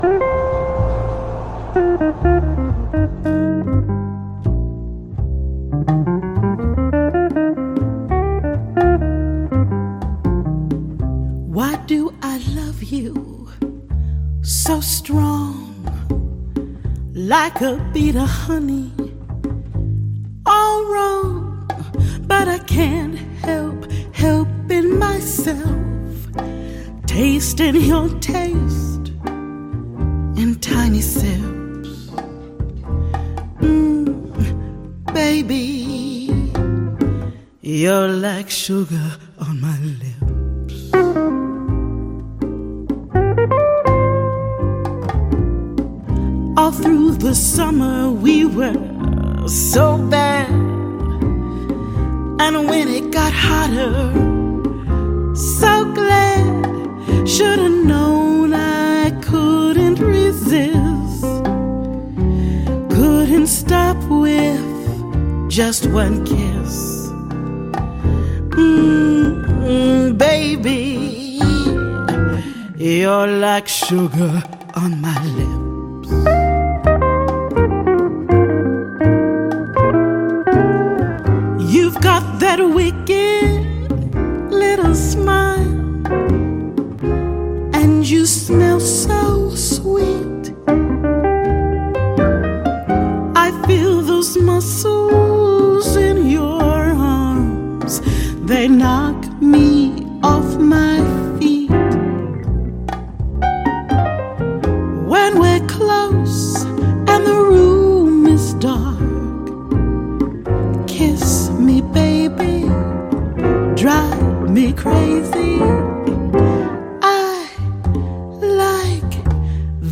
Why not browse the many other interviews on this site and get to know the back stories to the artists you know and love